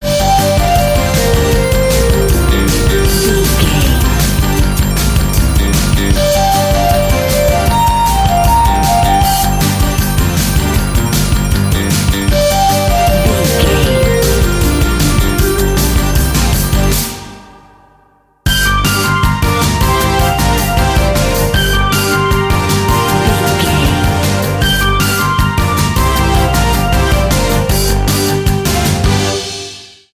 Dorian
Fast
chaotic
driving
energetic
futuristic
bass guitar
synthesiser
percussion
electric piano